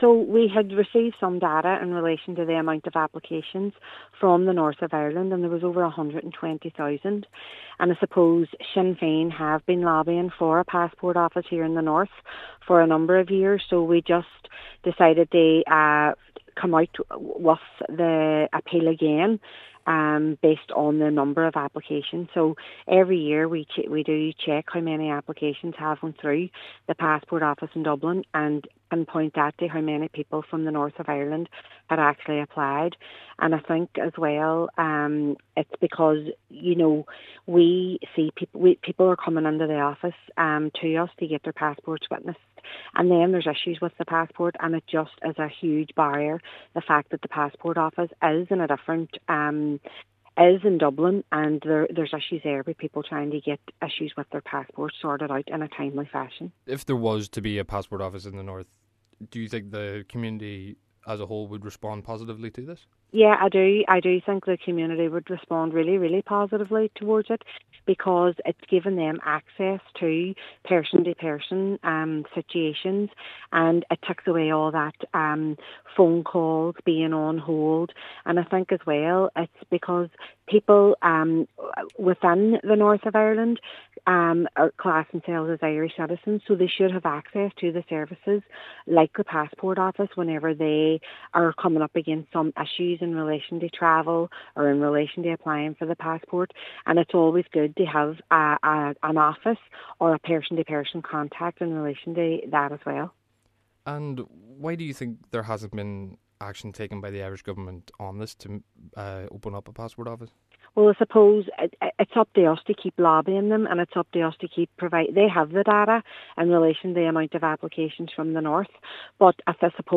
Derry City and Strabane Cllr Aisling Hutton says that opening an office in Derry or Belfast would help issues that occur in the postal service: